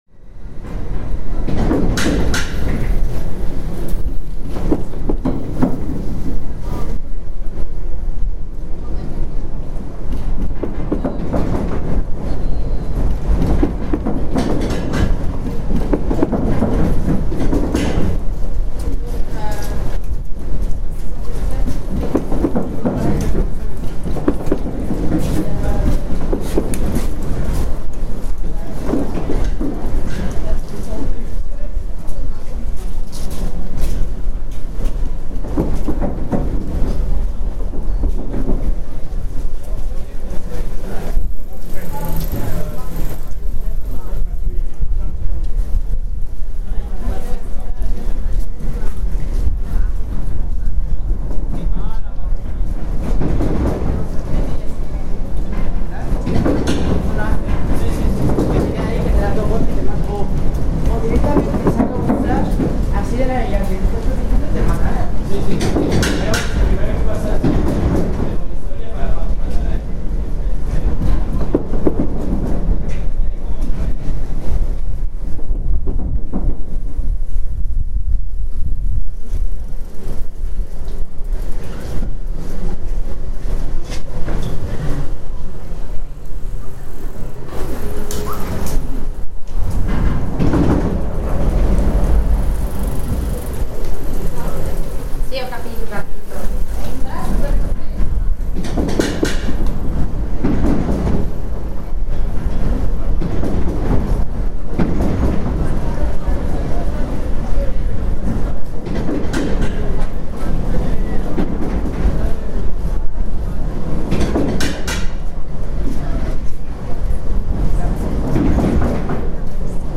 This was recorded in June 2018 on the Lower Mall path under Hammersmith Bridge, London, during a busy time for pedestrian traffic; this was less than a year before the bridge completely closed for extensive repairs.